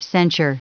Prononciation du mot censure en anglais (fichier audio)
Prononciation du mot : censure